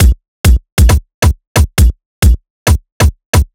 Kicks Ridim 135.wav